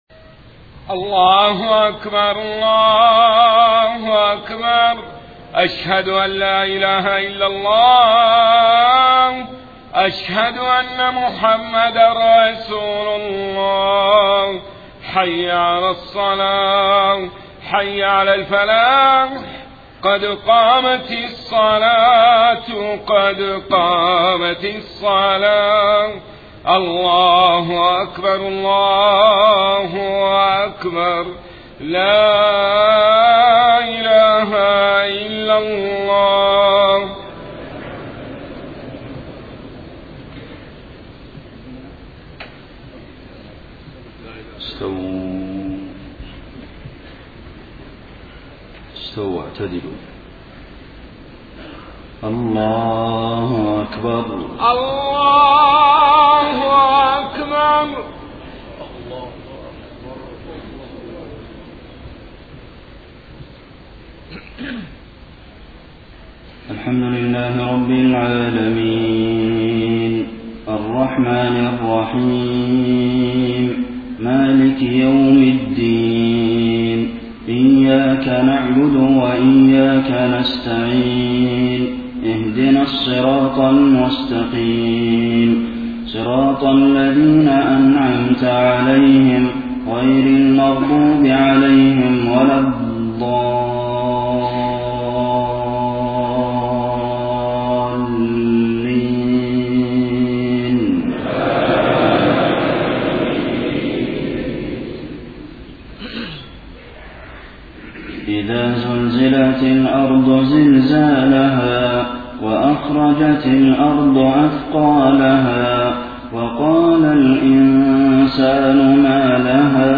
صلاة المغرب 14 ربيع الأول 1431هـ سورتي الزلزلة و الهمزة > 1431 🕌 > الفروض - تلاوات الحرمين